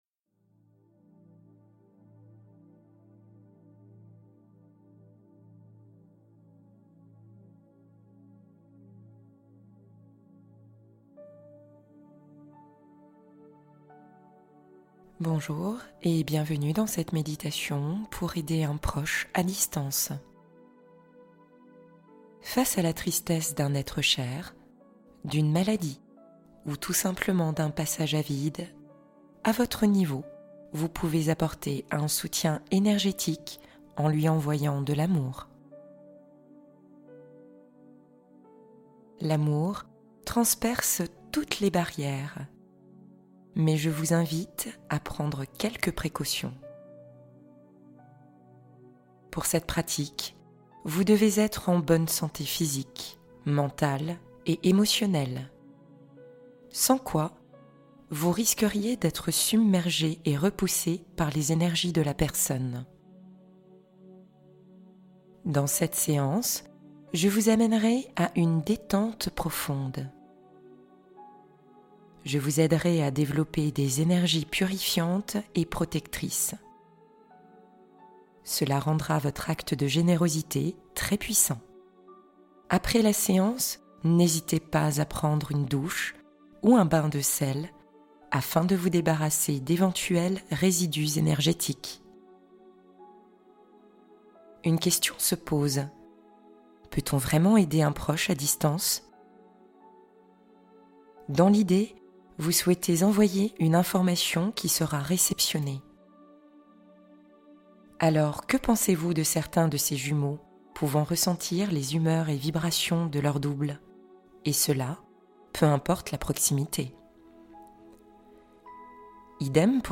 Envoyez de l'amour à distance et guérissez vos relations | Méditation connexion énergétique